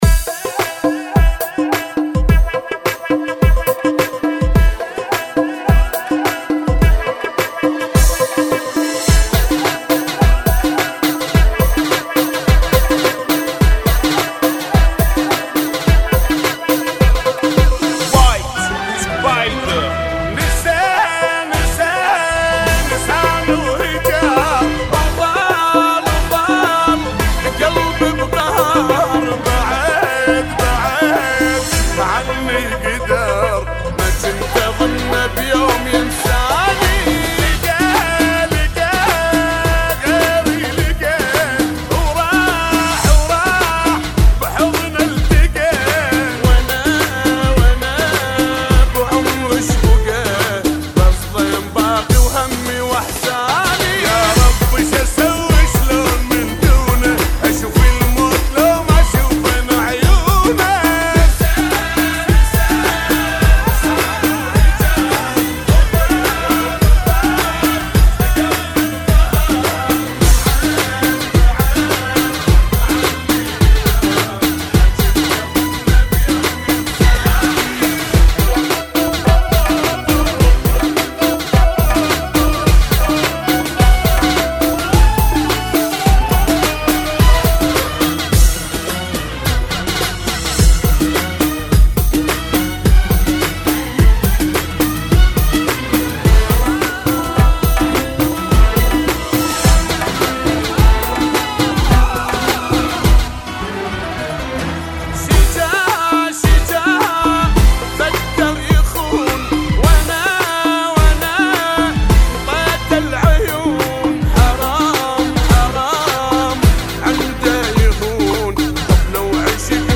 Funky [ 106 Bpm